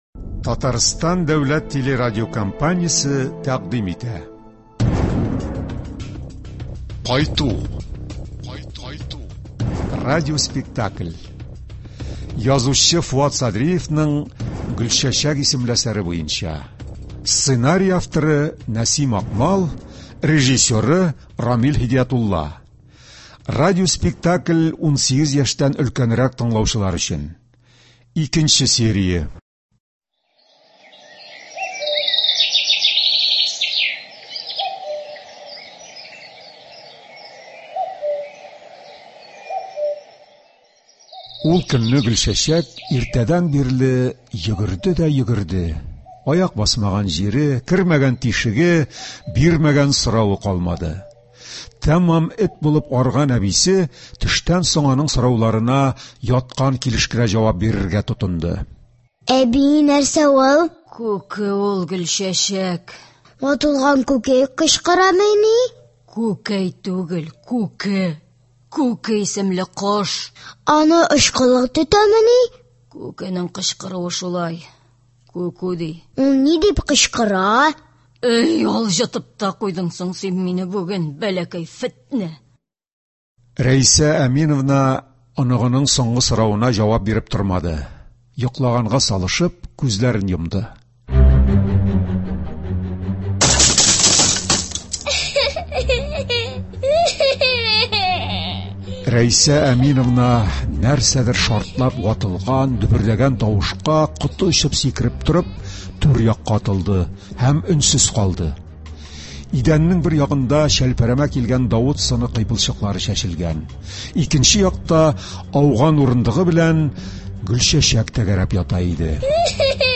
“Кайту”. Радиоспектакль.
Радиоспектакль премьерасы (27.11.23)